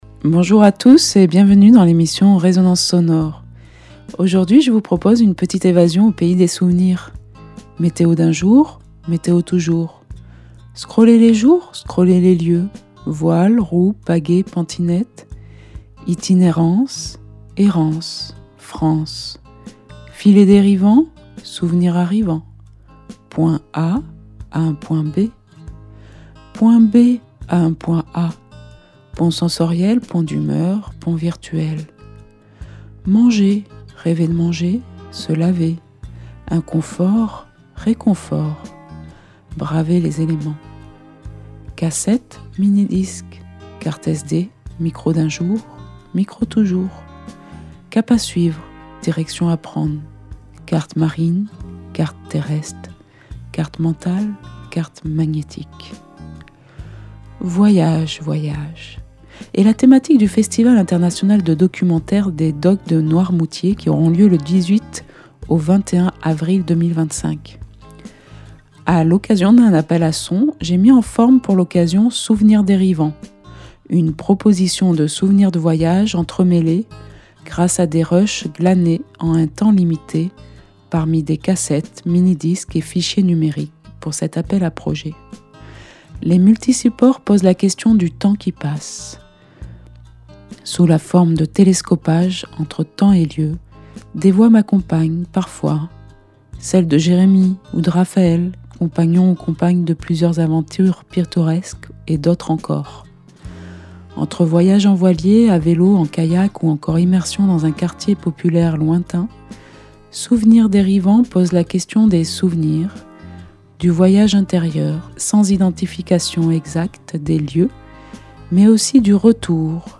« « Voyage, voyages », est la thématique des Docs de Noirmoutier de cette année, je ne pouvais pas y couper…Cette 4 ème édition se déroulera entre le 18 et le 21 avril 2025 .Souvenirs dérivants est une proposition de souvenirs de voyages entremêlés, grâce à des rushs glanés en un temps limité parmi des cassettes, mini-disques et fichiers numériques pour cet appel à créations sonores.